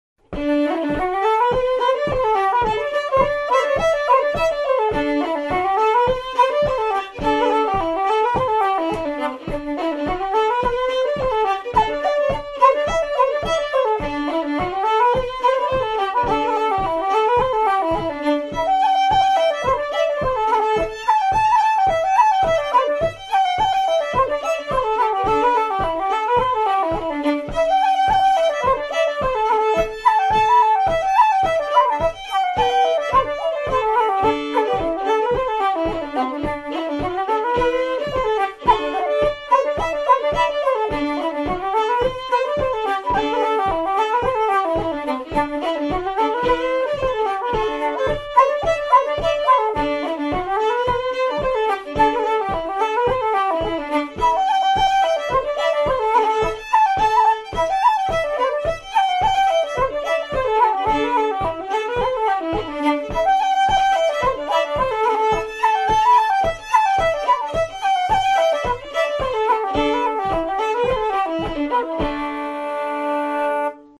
Sporting Paddy reel set